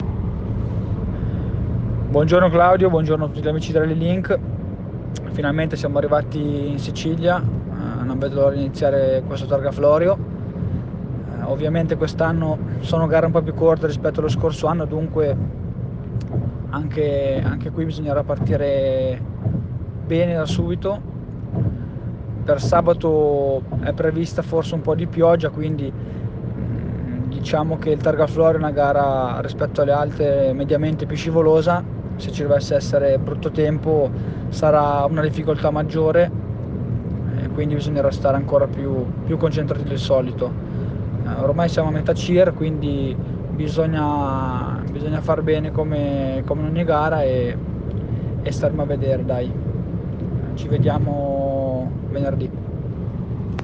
Interviste Rally Targa Florio 2020
Interviste pre-gara